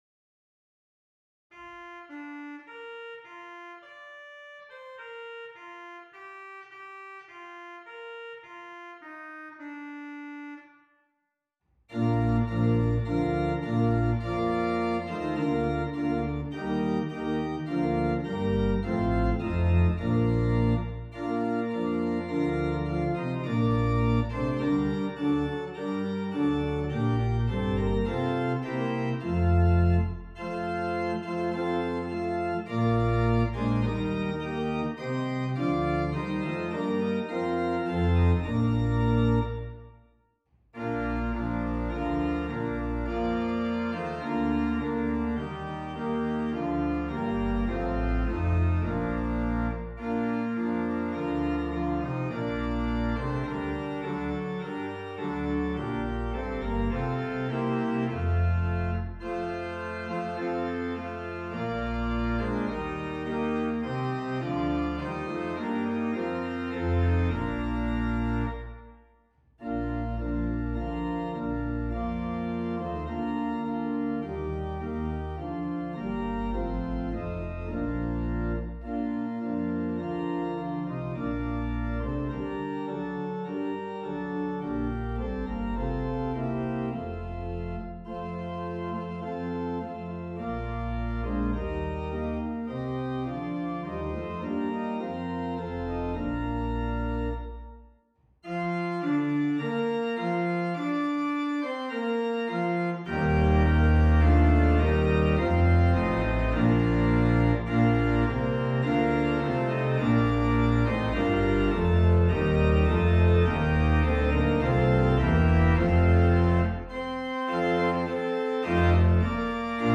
Organ: Moseley
lights-abode-celestial-salem-stannesmoseley.wav